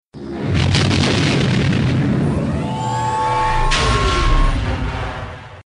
hyperdrive.mp3